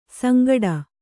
♪ sangaḍa